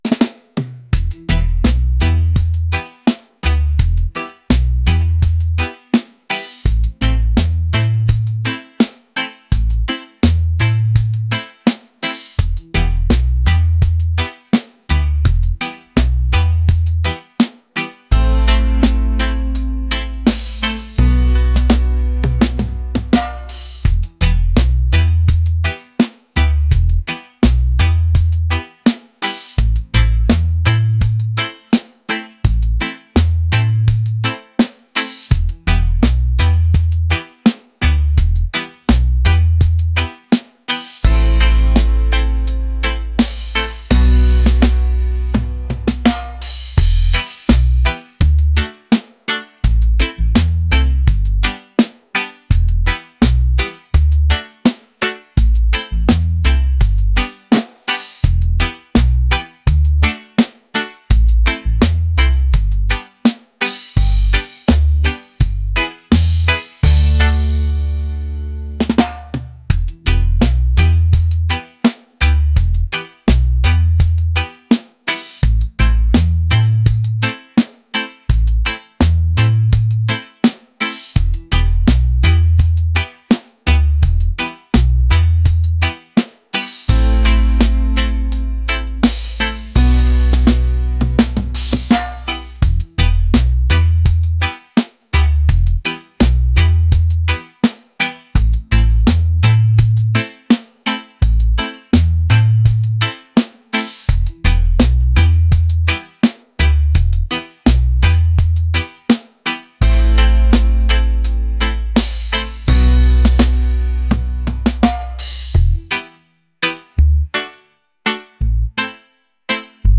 laid-back | reggae | groovy